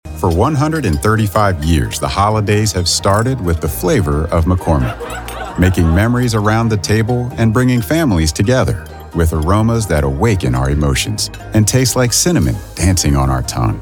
Commercial / Home / Cozy / Warm
North American General American, North American US Mid-Atlantic
Middle Aged